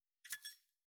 359七味を振る,一味,唐辛子,調味料,カシャカシャ,サラサラ,パラパラ,ジャラジャラ,サッサッ,ザッザッ,シャッシャッ,シュッ,パッ,サッ,
効果音厨房/台所/レストラン/kitchen